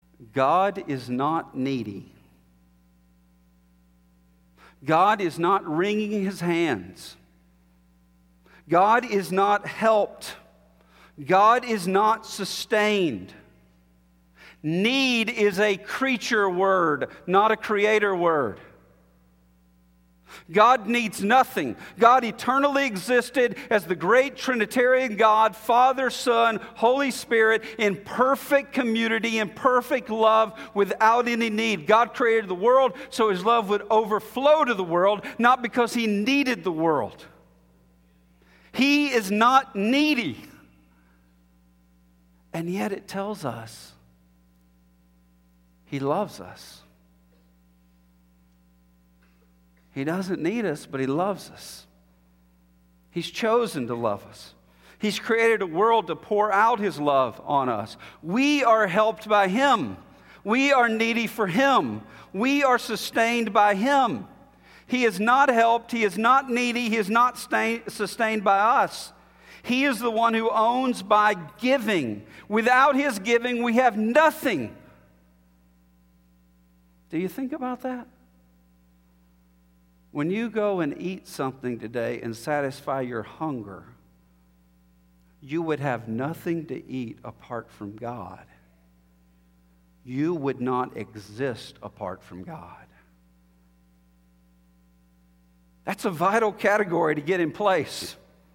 Sermon Excerpt